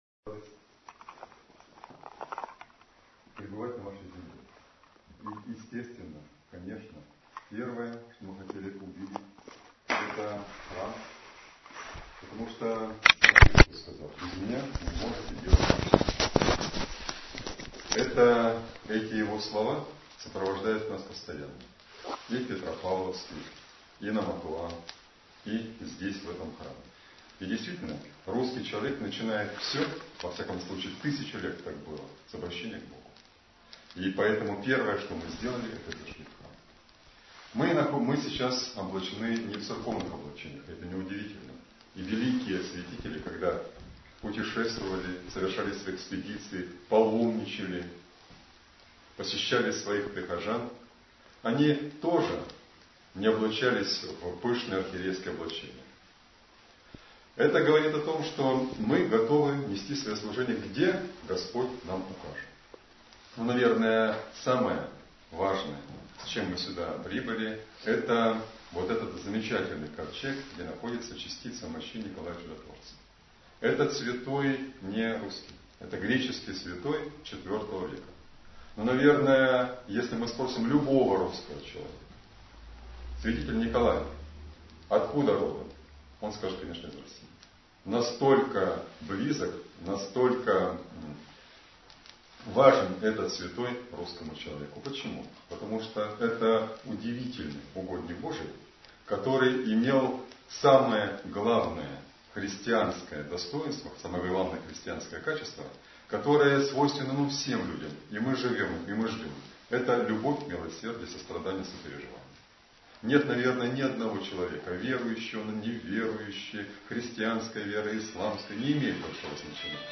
Слово архиепископа Игнатия в храме Святителя Иннокентия Московского в г. Северо-Курильске.